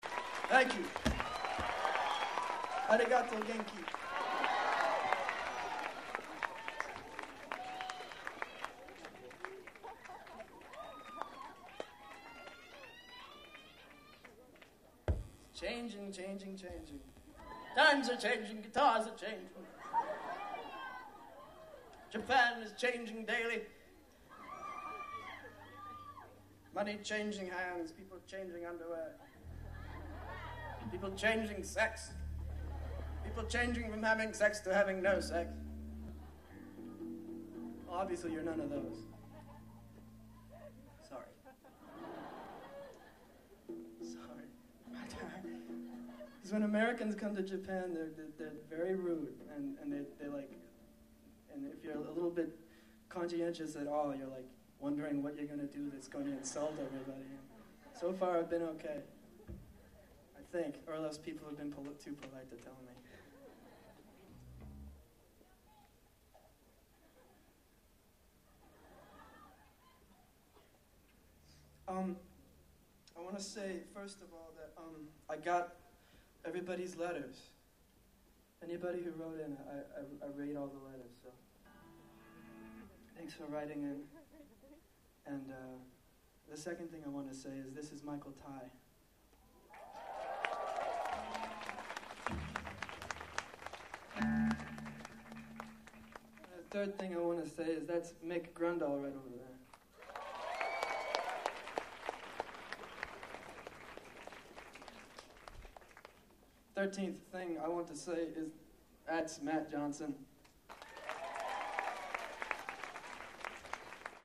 Live 1995
This cd shows more than any cd I've got with Jeff his ability to communicate with the audience during concert, particularly on two occations:
1) end of track 3 he holds a monologue taking different voices.